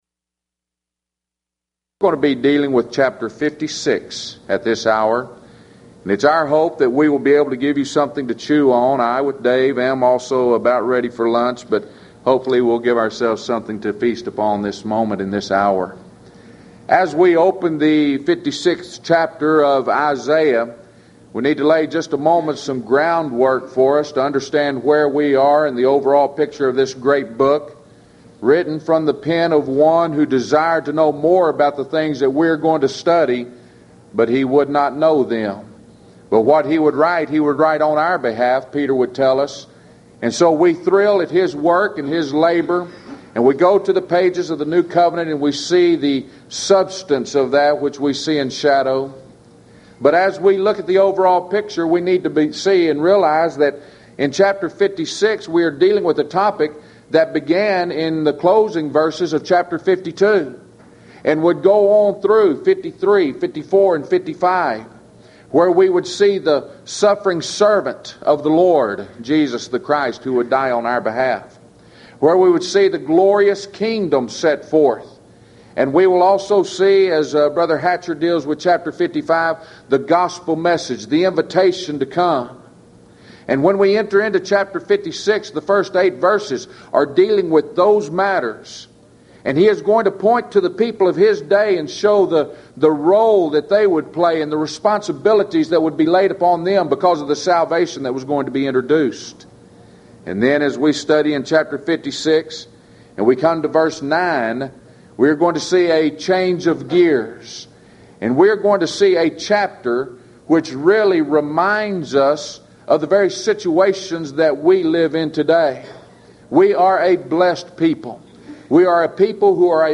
Event: 1996 HCB Lectures Theme/Title: The Book Of Isaiah - Part II
lecture